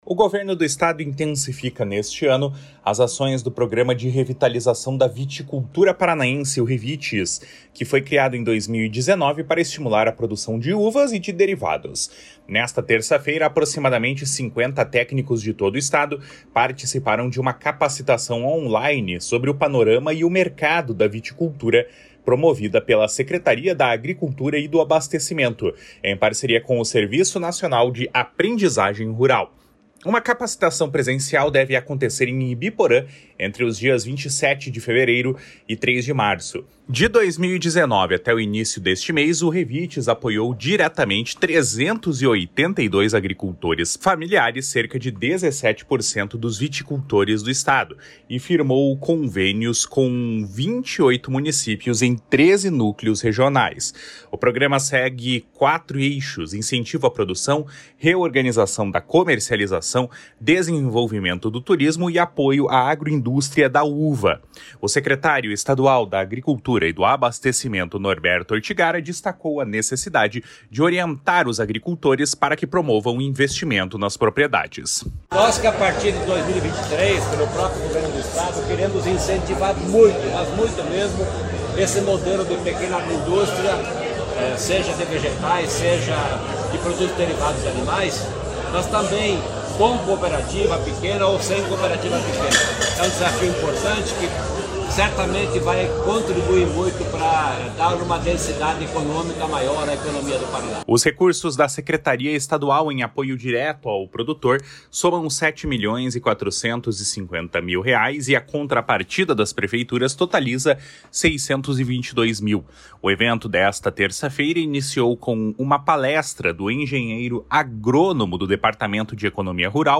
O secretário estadual da Agricultura e do Abastecimento, Norberto Ortigara, destacou a necessidade de orientar os agricultores para que promovam investimento nas propriedades. // SONORA NORBERTO ORTIGARA //